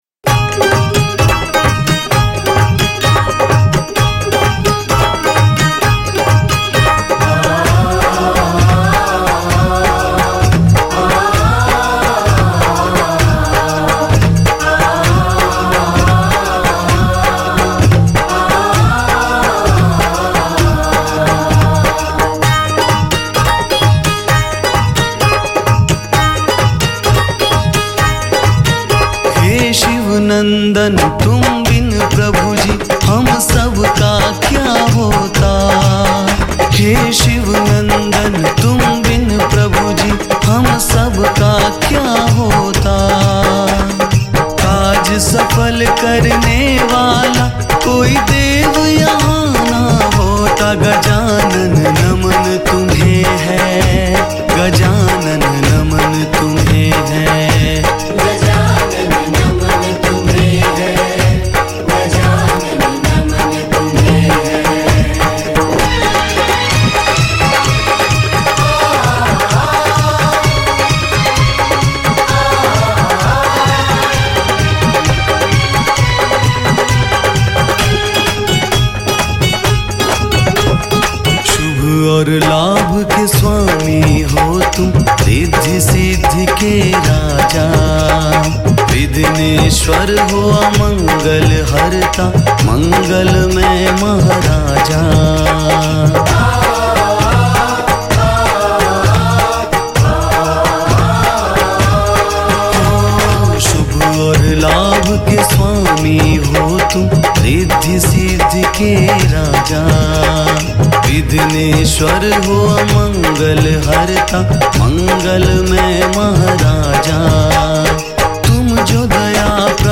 Ganesh Bhajan
Hindi Bhajan